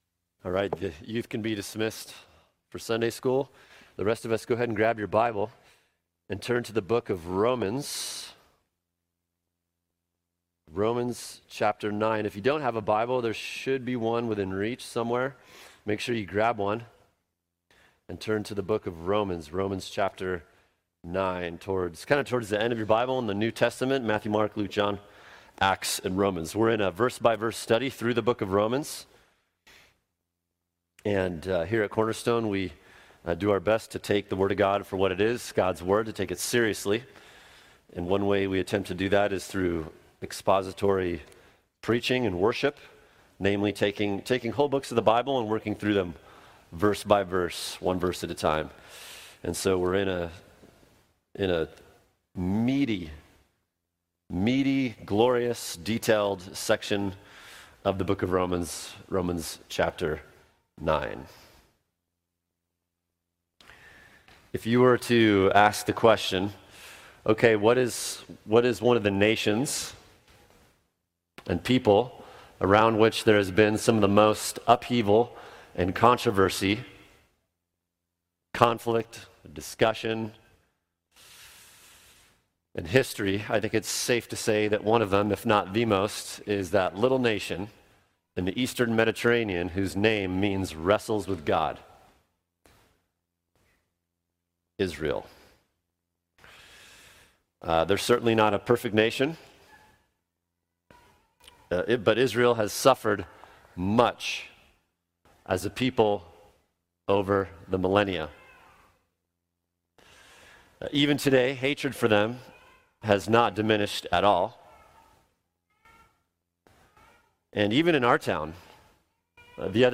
[sermon] Has God’s Word Failed? Romans 9:6-10 | Cornerstone Church - Jackson Hole